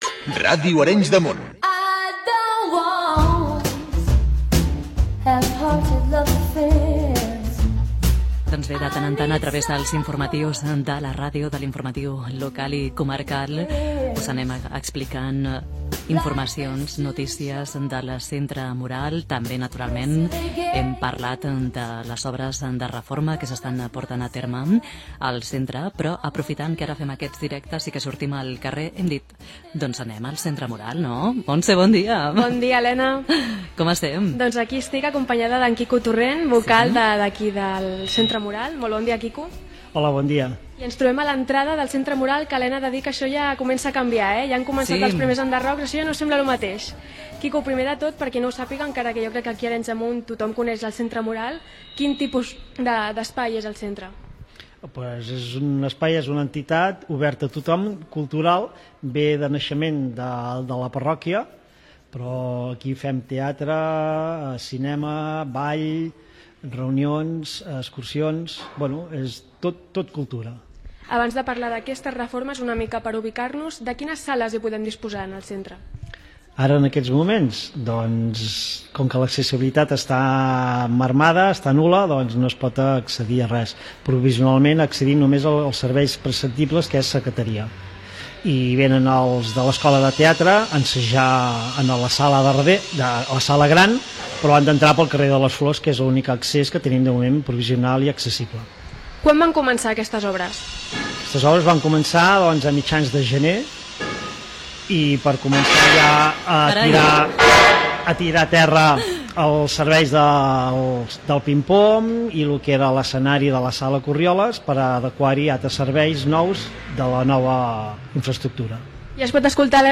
Indicatiu, connexió amb el centre Moral, activitats del centre i reformes.
Informatiu